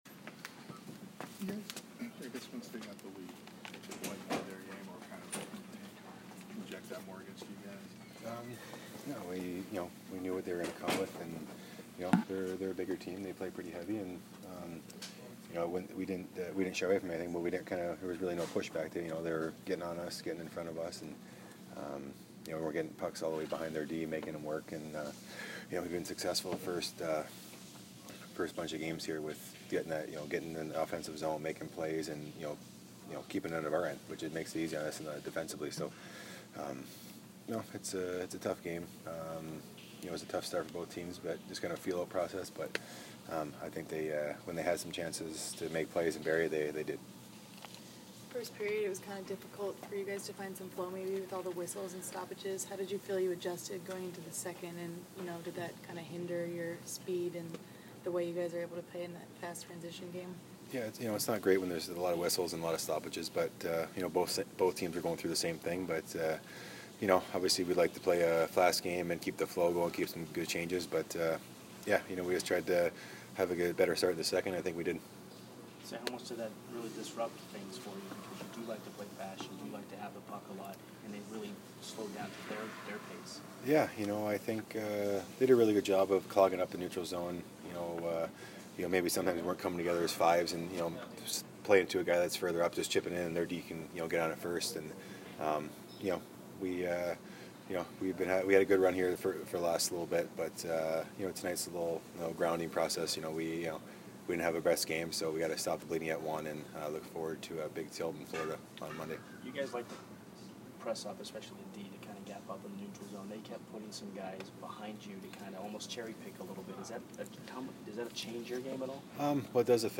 Dan Girardi Post-Game 10/28